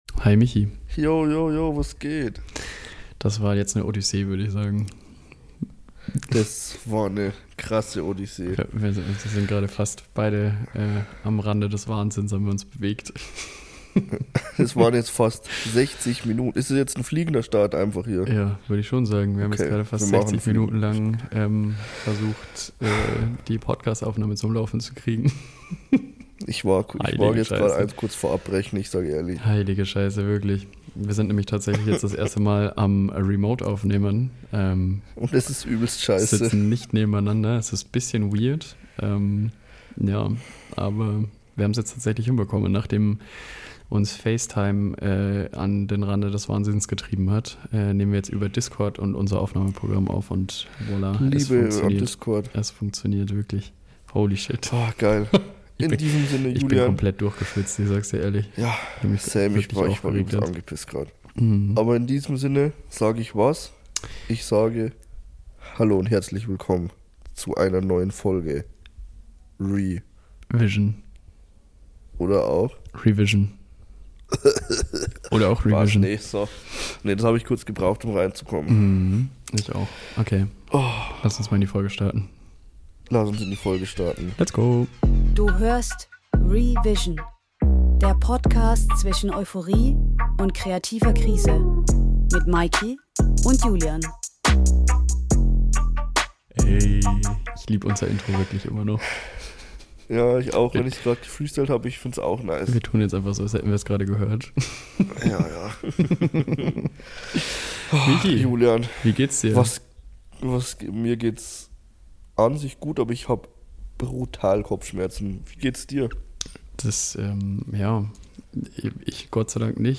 Zwei völlig ausgebrannte Video-Creator treffen sich zum Talk.